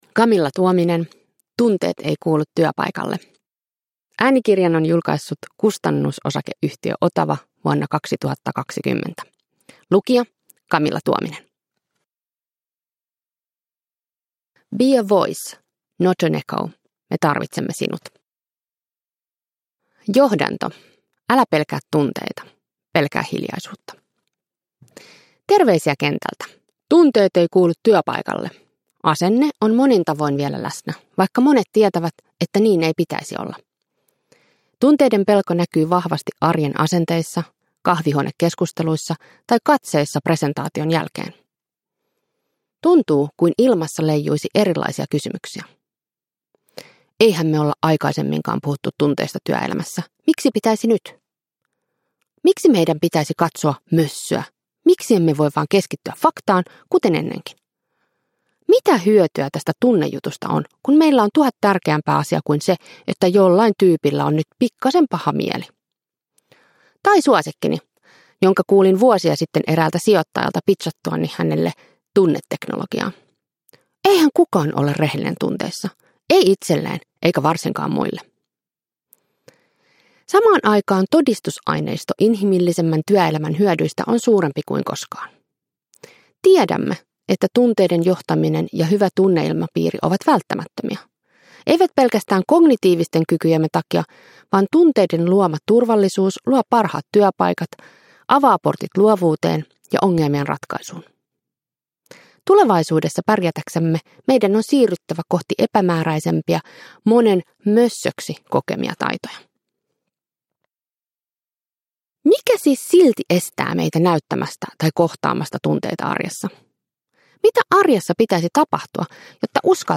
Tunteet ei kuulu työpaikalle – Ljudbok – Laddas ner